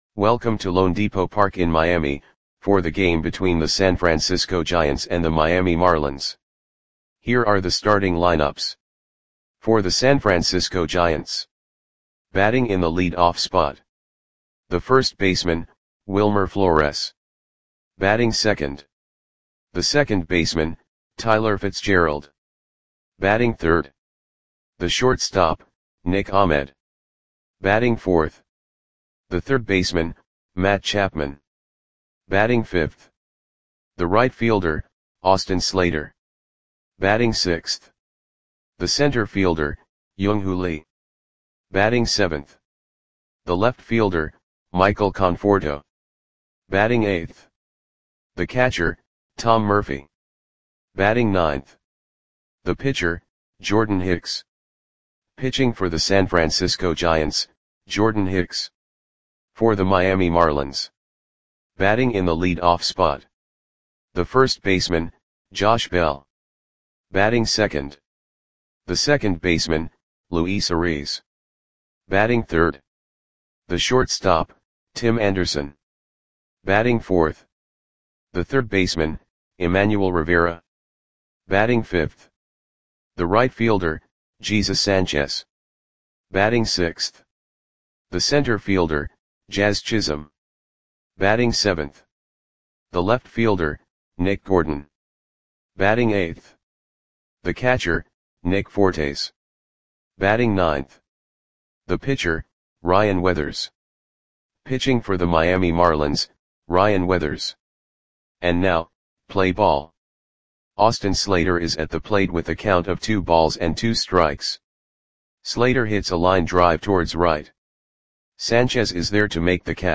Audio Play-by-Play for Miami Marlins on April 16, 2024
Click the button below to listen to the audio play-by-play.